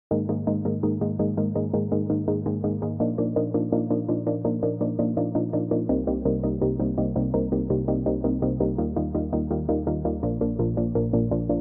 FMP_Kit2_83_Verse_Pluck_Chords_C_Minor
fairlane-synth.mp3